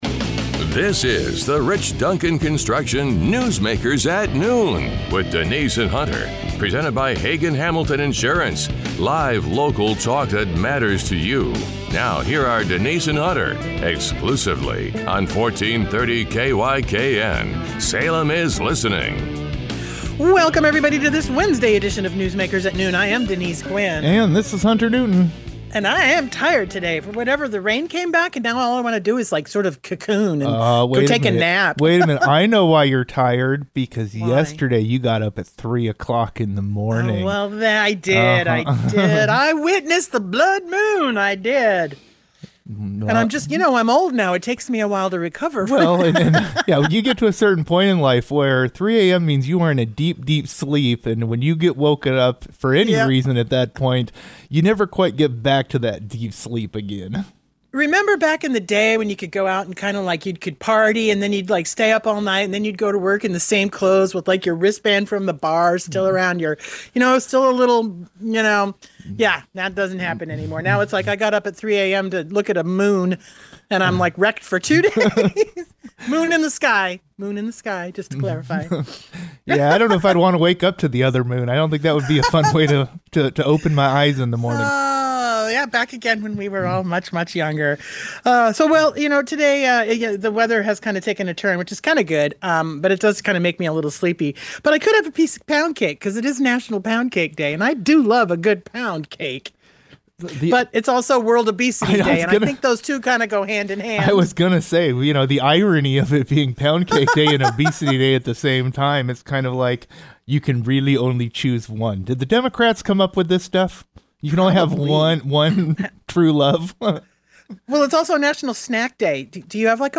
The hosts then turn to developments in Oregon politics, including the confirmation that the legislative session’s Sine Die deadline is approaching. They also discuss reactions online to comments from Oregon Secretary of State Tobias Read about the state’s vote-by-mail system and examine the broader conversation surrounding election confidence and turnout.